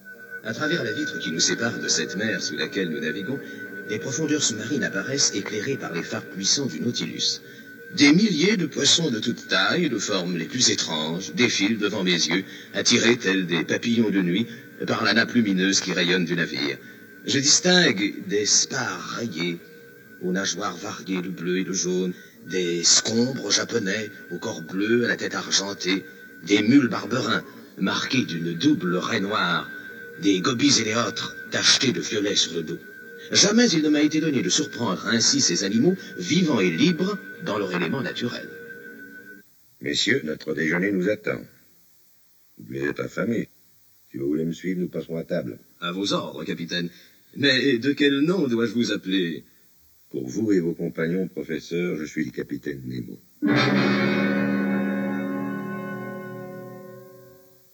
Il est tiré d’une version audio de “20 000 Lieues sous les mers”, narrée par Jean Gabin avec une musique composée par Jean-Michel Jarre.